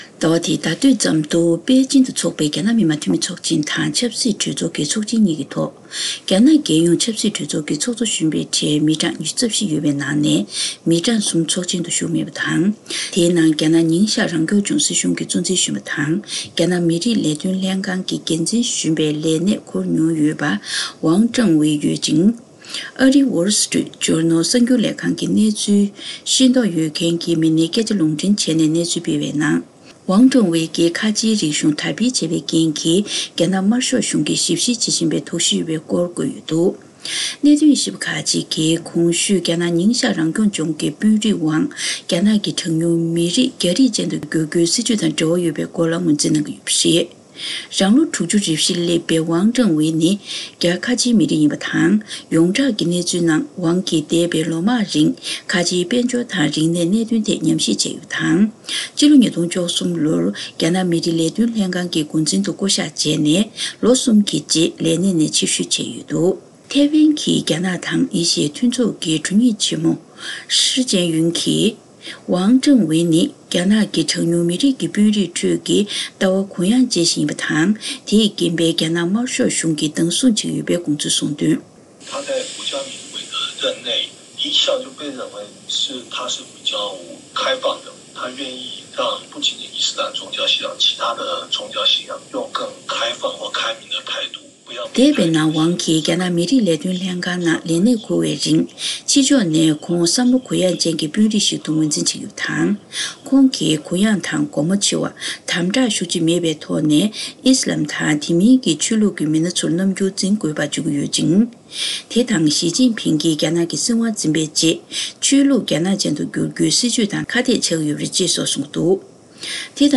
སྒྲ་ལྡན་གསར་འགྱུར།
གནད་དོན་དབྱེ་ཞིབ་པས་འགྲེལ་བརྗོད་གནང་གི་ཡོད་པའི་སྐོར།